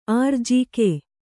♪ ārjike